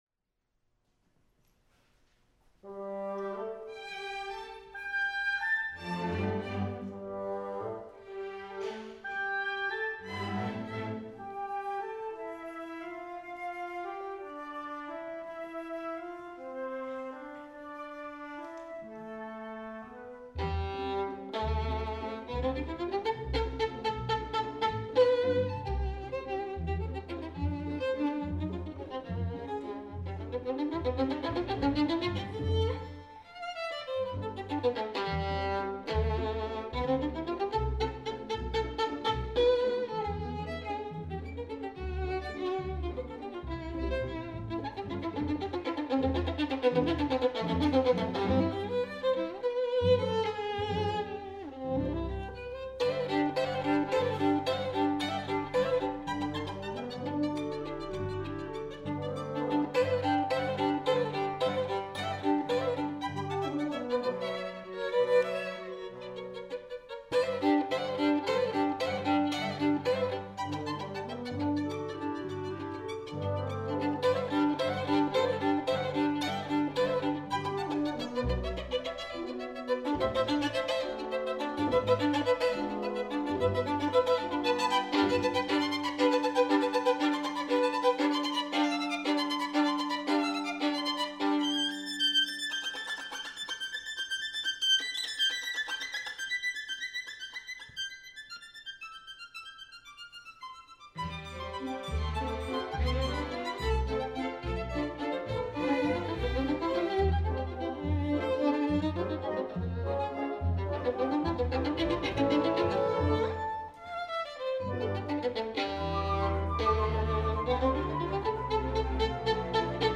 Recordings of the Silicon Valley Symphony
Concert recordings
Tchaikovsky: Valse Scherzo for Violin and Orchestra
Saturday, March 2, 2013: Holy Trinity Episcopal Church